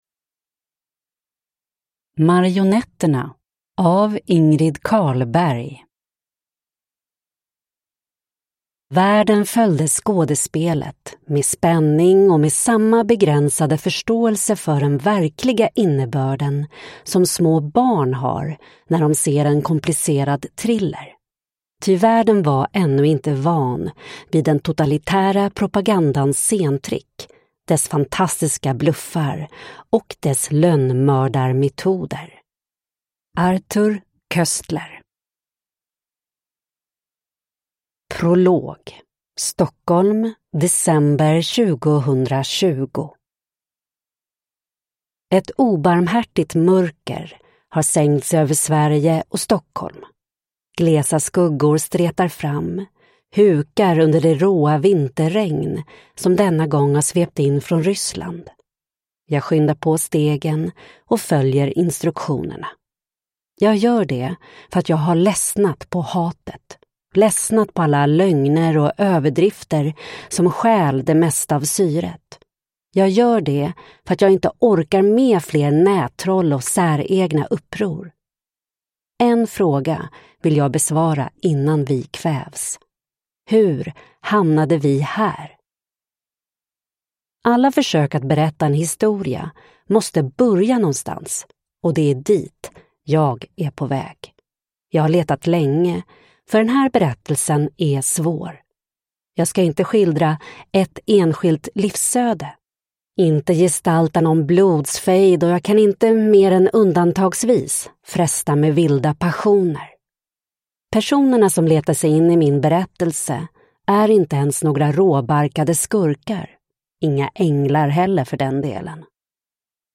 Marionetterna : en berättelse om världen som politisk teater – Ljudbok